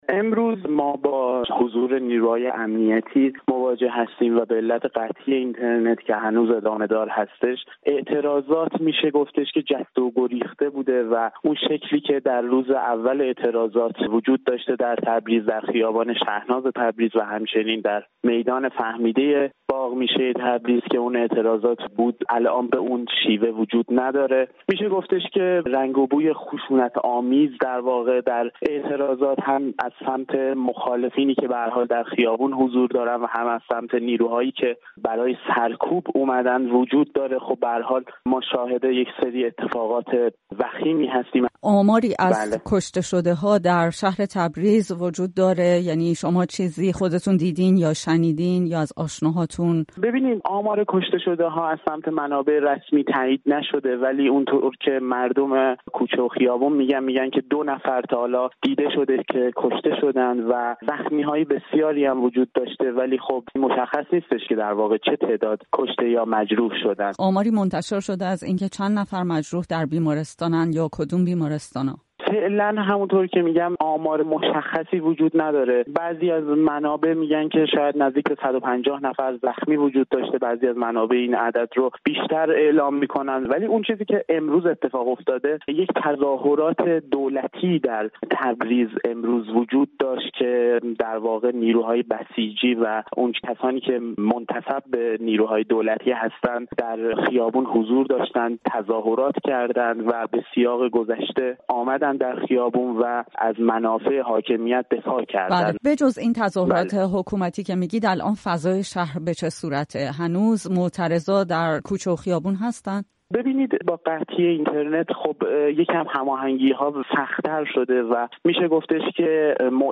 فضای امنیتی و استقرار نیروهای پلیس و یگان ویژه در تبریز به روایت یک فعال مدنی